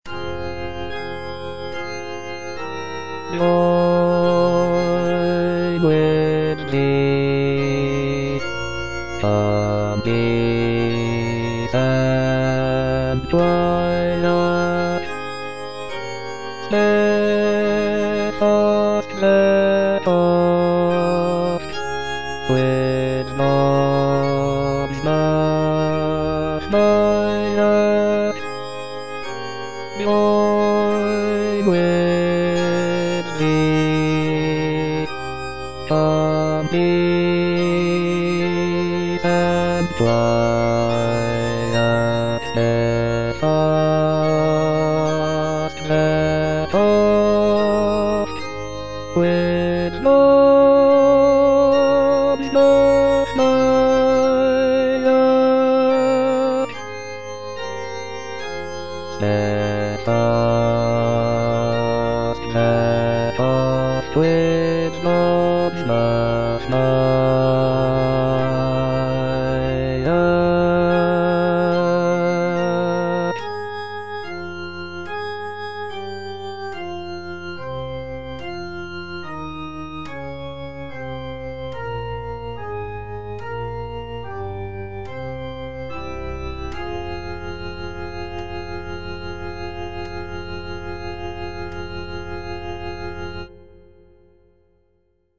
G.F. HÄNDEL - L'ALLEGRO, IL PENSEROSO ED IL MODERATO HWV55 Join with thee calm Peace and Quiet - Bass (Voice with metronome) Ads stop: auto-stop
The composition features a mixture of solo arias, choruses, and instrumental interludes, reflecting the different moods and emotions depicted in the texts.